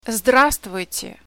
How to say "Hello" In Russian